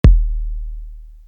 Crushed Linen Kick.wav